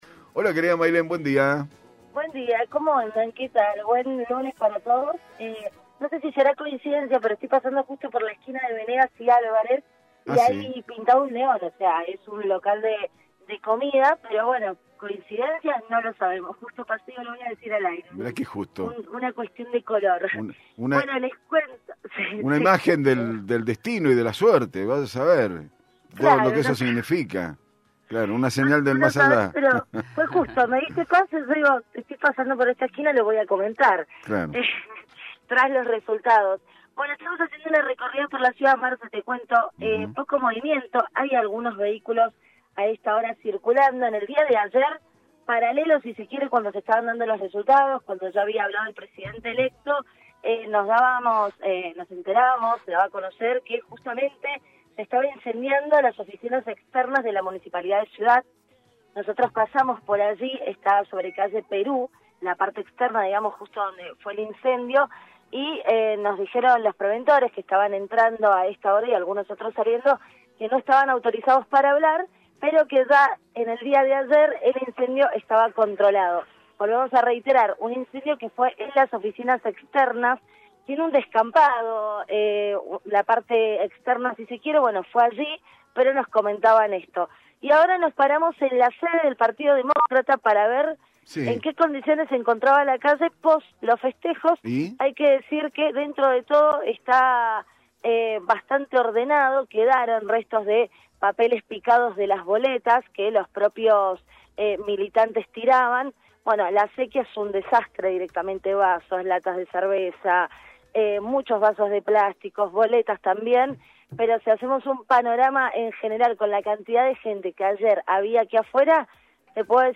LVDiez - Radio de Cuyo - Móvil de LVDiez desde microcentro